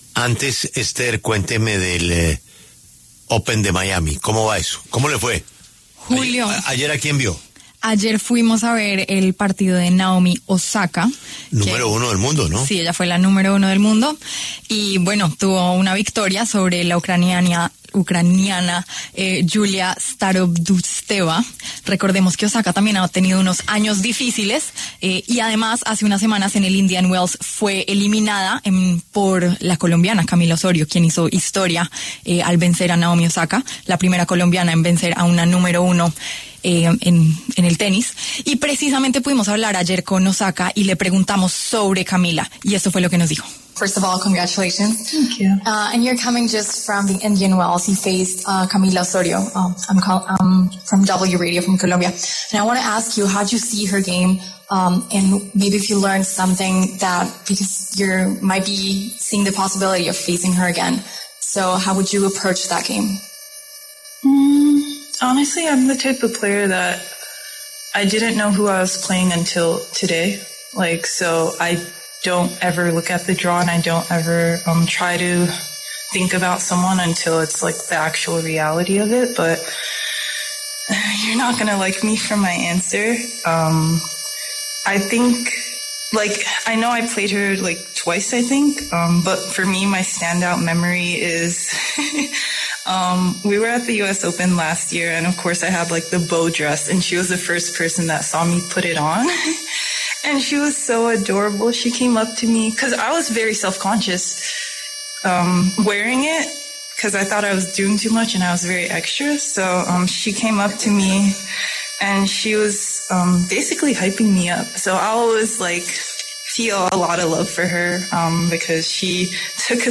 La tenista japonesa Naomi Osaka conversó con La W a propósito de su participación en el Miami Open 2025, cuya primera ronda femenina ya está en marcha.
Una periodista le recordó a Osaka que el consejo que esta le dio a Osorio en un torneo fue que “ya podía coleccionar las toallas”, un detalle que la japonesa no recordaba, pero que le causó risa.
“Wow, ¿ese es el mejor consejo que he dado? ¡Dios mío! Necesito dar mejores consejos”, advirtió, entre risas.